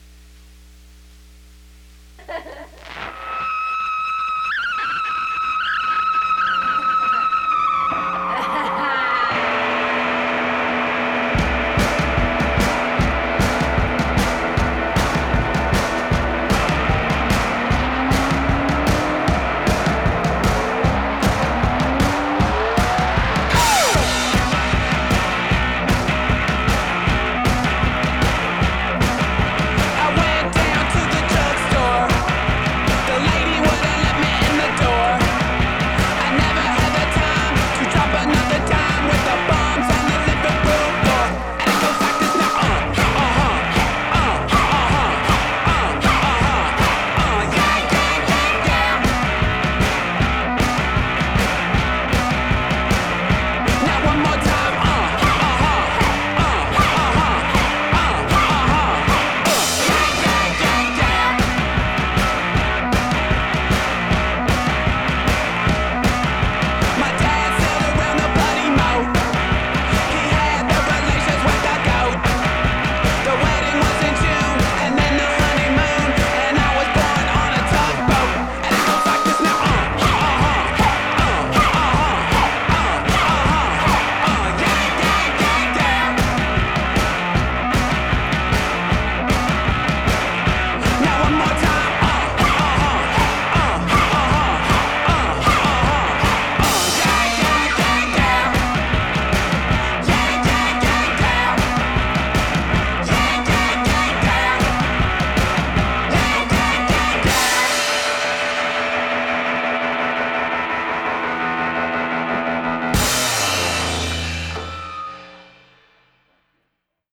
lei suona la batteria, lui la chitarra